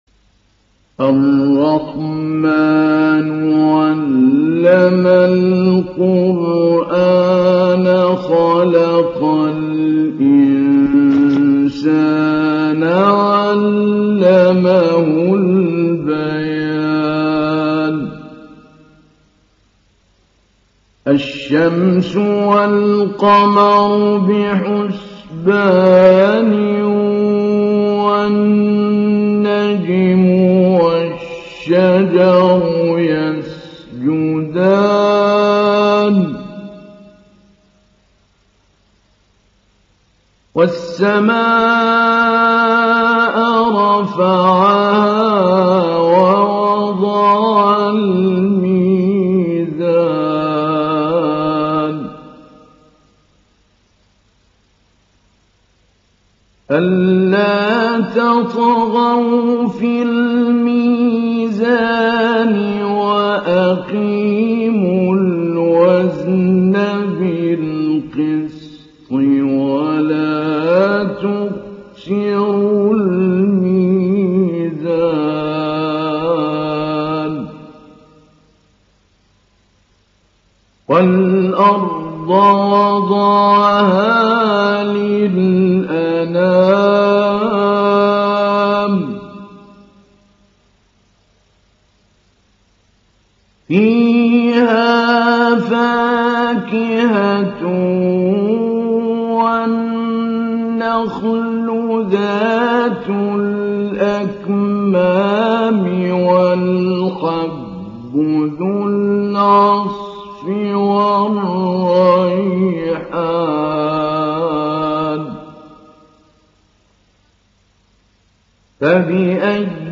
Surah Ar Rahman Download mp3 Mahmoud Ali Albanna Mujawwad Riwayat Hafs from Asim, Download Quran and listen mp3 full direct links
Download Surah Ar Rahman Mahmoud Ali Albanna Mujawwad